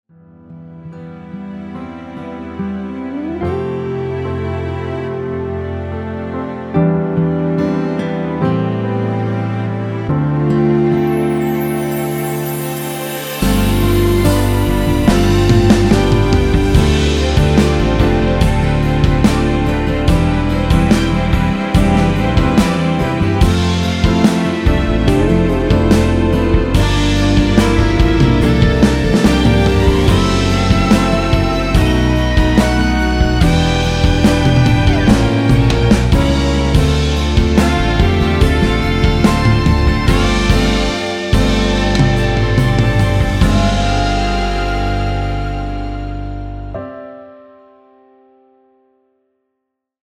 원키 1절후 후렴으로 진행되게 편곡한 MR 입니다.(미리듣기및 가사 참조)
노래가 바로 시작 하는 곡이라 전주 만들어 놓았으며
6초쯤에 노래 시작 됩니다.(멜로디 MR 참조)
앞부분30초, 뒷부분30초씩 편집해서 올려 드리고 있습니다.
중간에 음이 끈어지고 다시 나오는 이유는